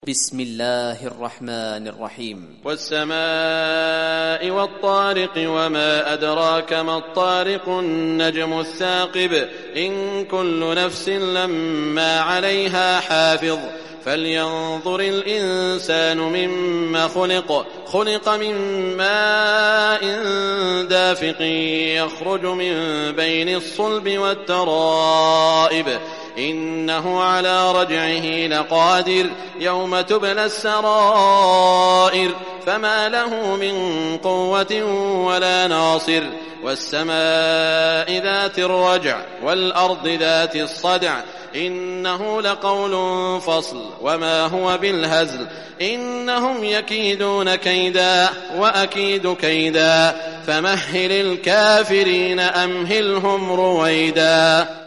Surah Tariq Recitation by Sheikh Shuraim
Surah Tariq, listen or play online mp3 tilawat / recitation in Arabic in the beautiful voice of Sheikh Saud al Shuraim.